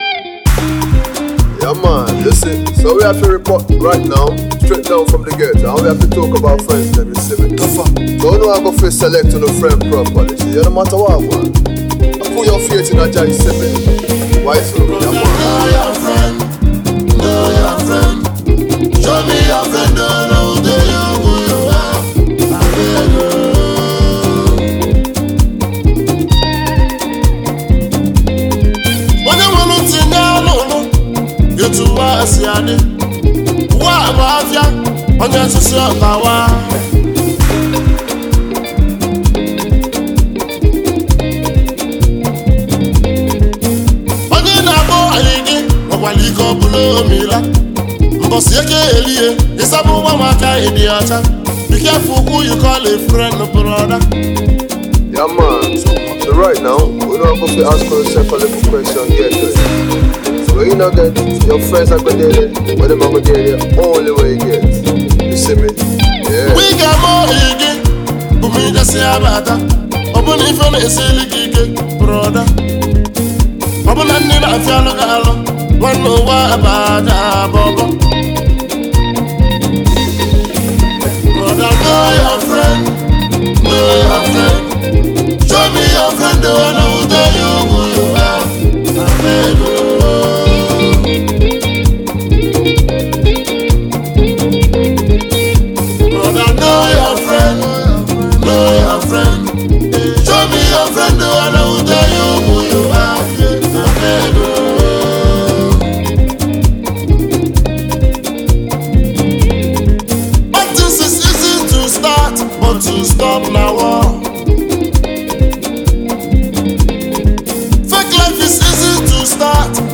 Nigerian talented and indigenous music duo